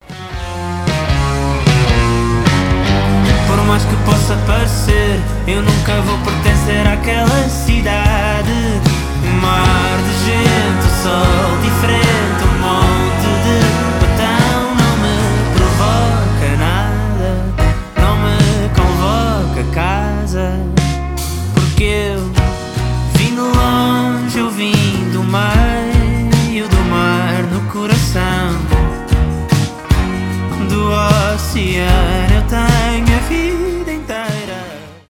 поп
гитара , приятные